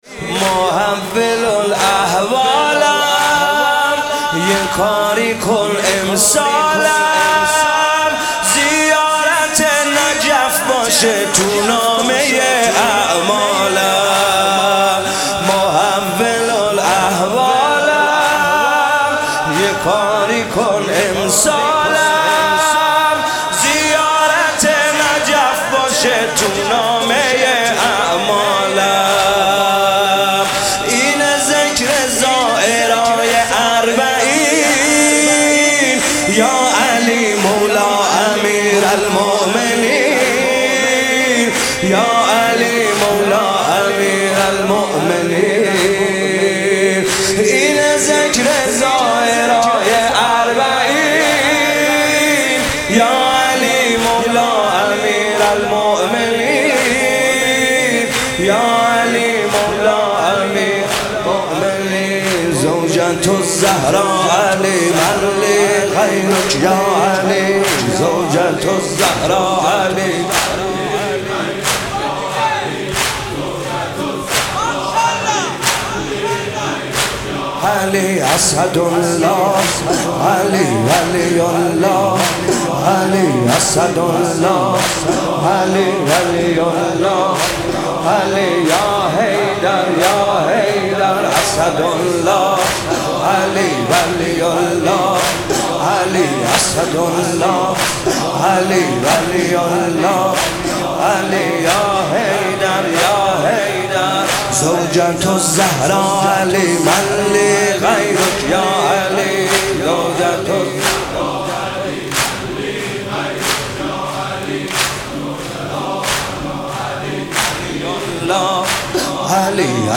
شب 19 رمضان 97 - زمینه محول الاحوال یه کاری کن امسال